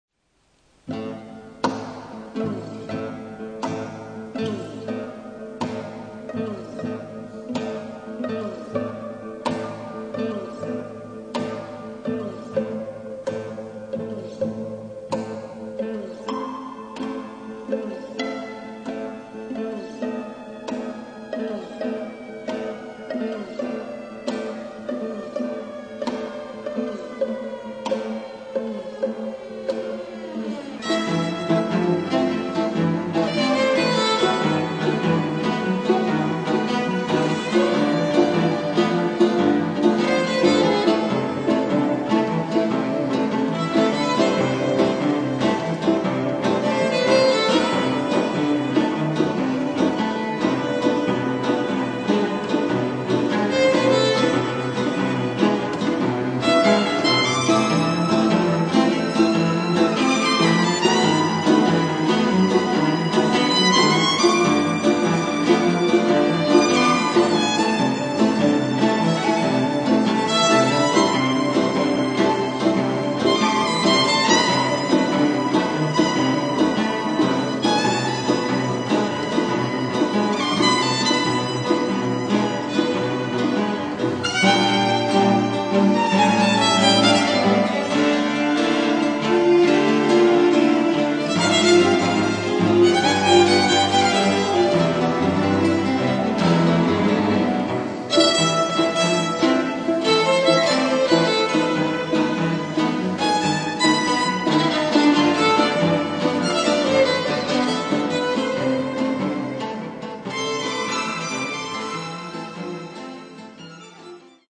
live
guitar and string trio